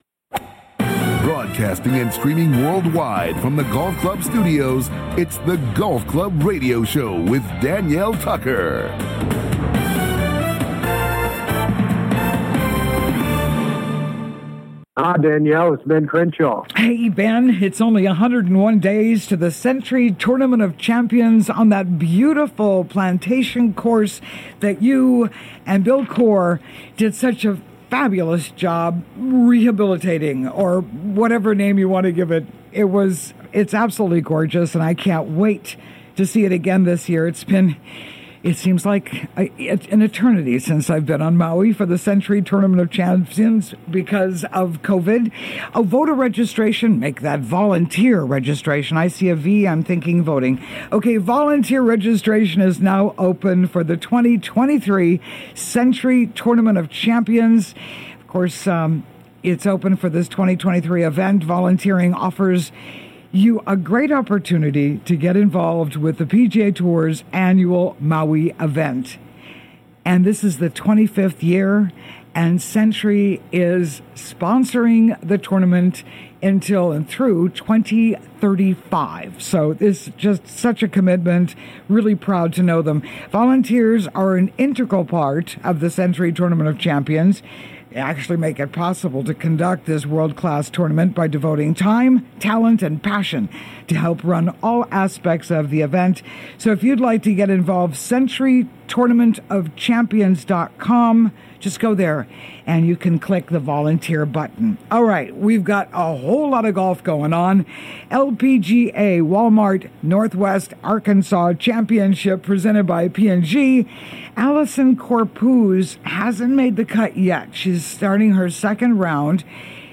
COMING TO YOU LIVE FROM THE GOLF CLUB STUDIOS ON LOVELY OAHU�s SOUTH SHORE � WELCOME INTO THE GOLF CLUB HOUSE!